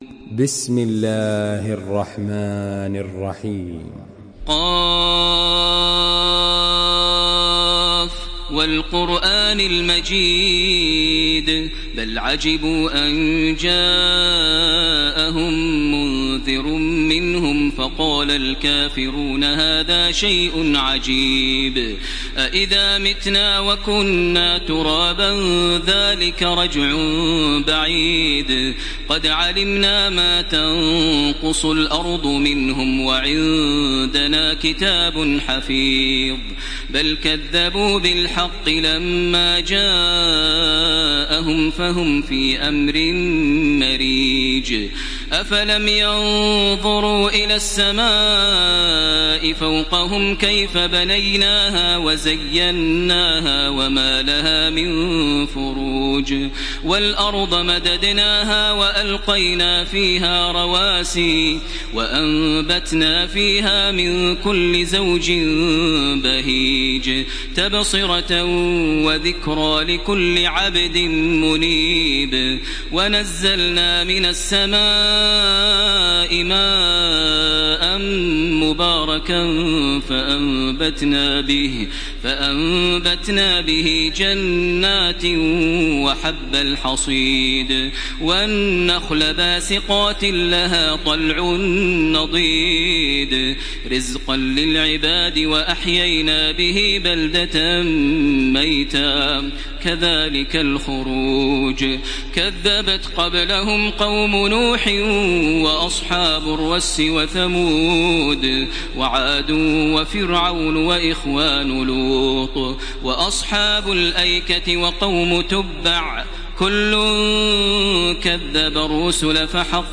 Surah Ad-Dariyat MP3 in the Voice of Makkah Taraweeh 1434 in Hafs Narration
Murattal